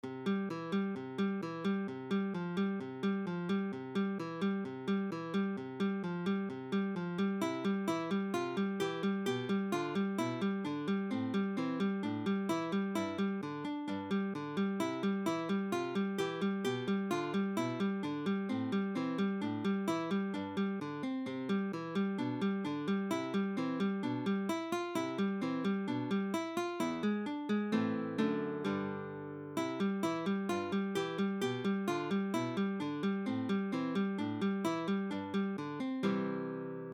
Essential Beethoven Guitar Tab - Fingerstyle / Plucking
So here is my version that is playable with 1 guitar.